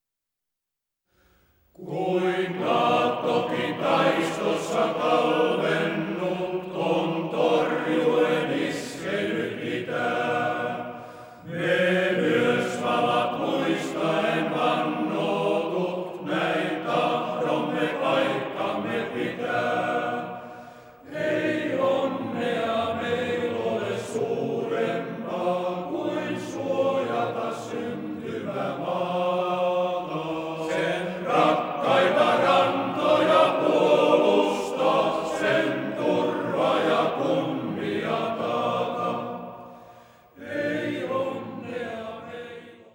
Kuoron lauluohjelmisto koostuu mieskuoro-ohjelmiston lisäksi myös erilaisista ja erimaalaisista sotilaslauluista , hengellisistä lauluista ja seranadeista.
Isänmaallisia lauluja